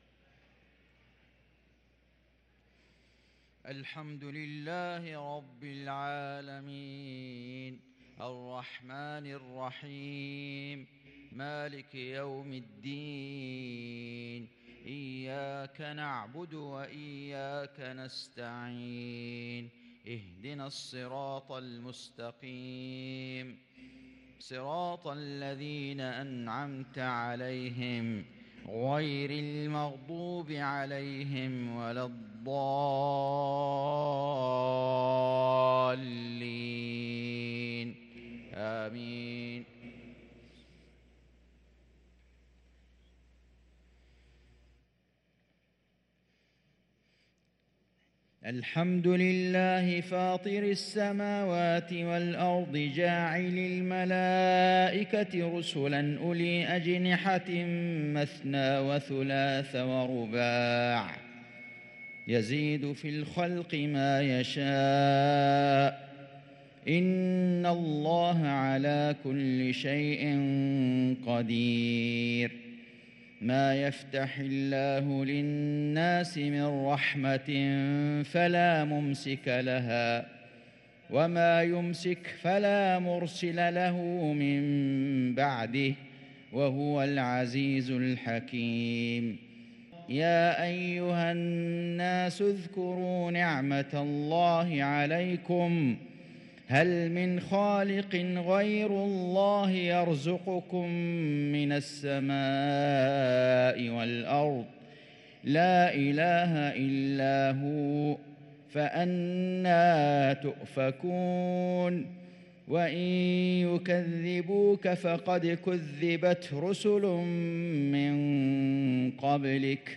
صلاة المغرب للقارئ فيصل غزاوي 18 ربيع الأول 1444 هـ
تِلَاوَات الْحَرَمَيْن .